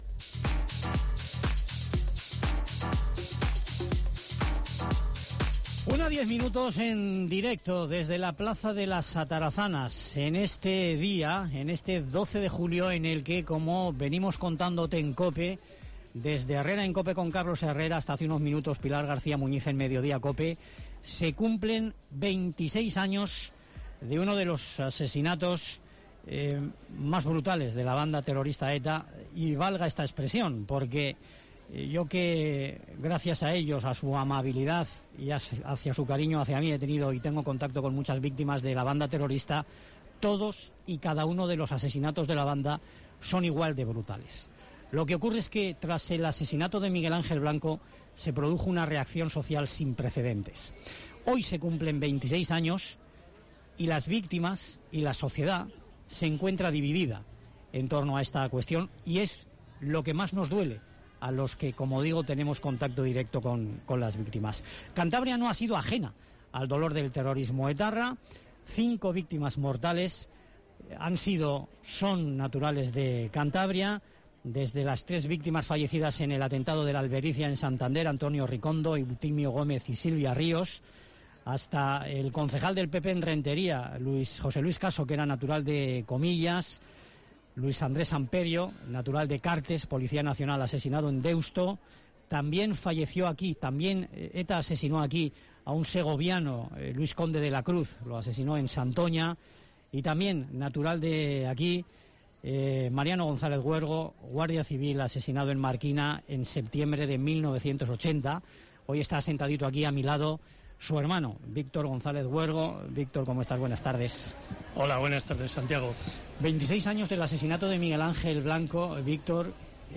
Programa especial de COPE con motivo del Día de la Justicia Gratuita y del Turno de Oficio